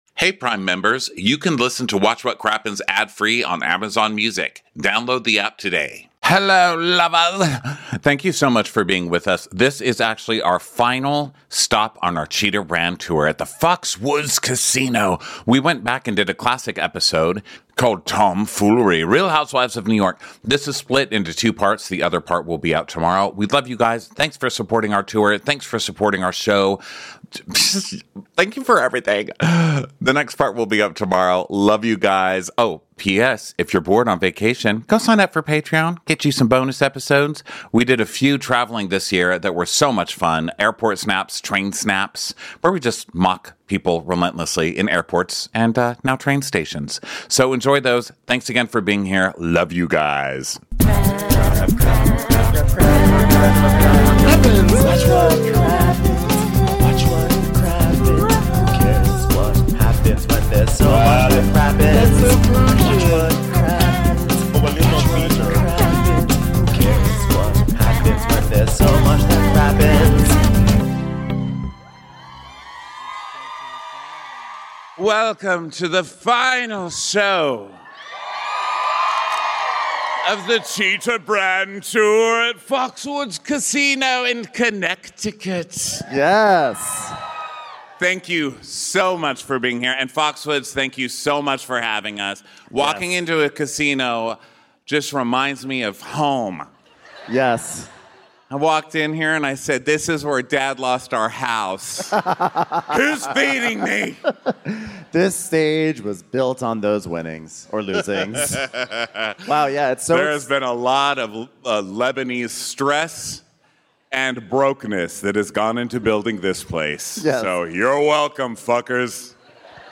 We're live at the Foxwoods casino to recap the classic Real Housewives of New York Episode "Tomfoolery".